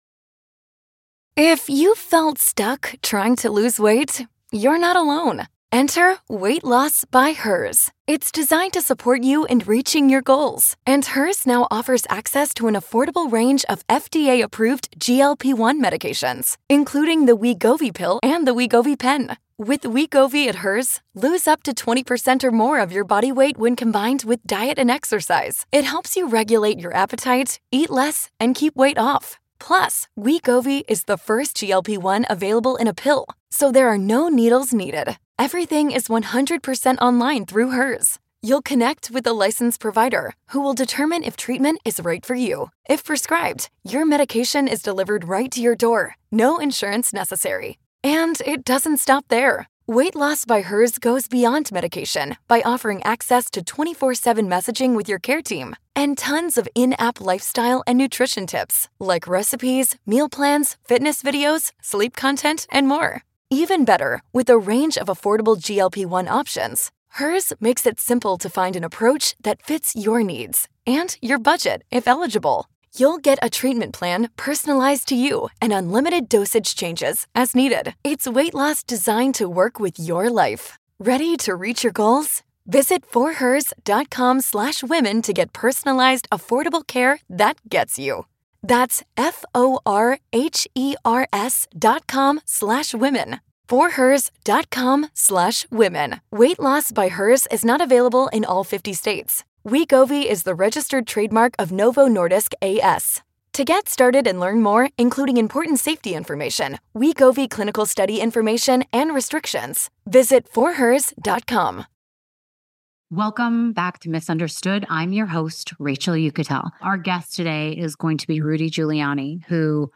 Today on Miss Understood with Rachel Uchitel we’ve got an EXCLUSIVE interview with the one and only Rudy Giuliani, the former Mayor of New York City, and a man who led the nation through one of its darkest hours.